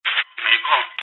radio_negative.mp3